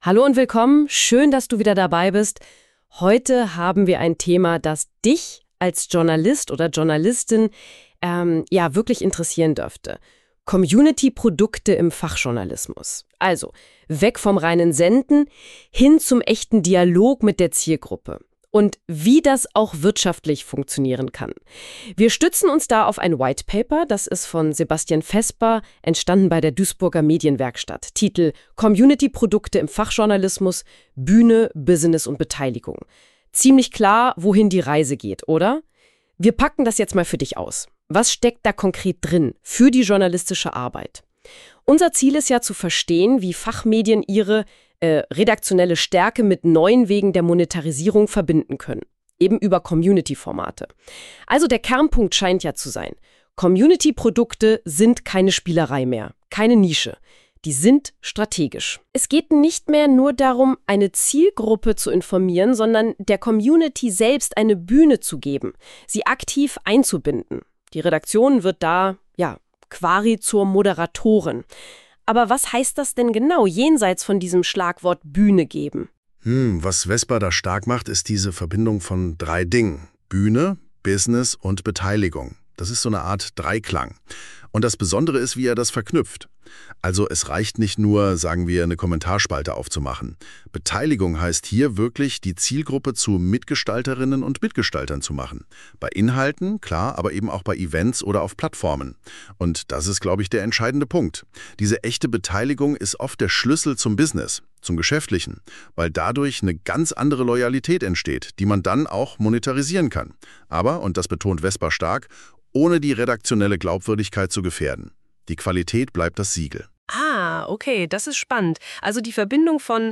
Unsere KI-Hosts diskutieren, wie sich redaktionelle Qualität mit Events, Awards und Austausch verbinden lässt.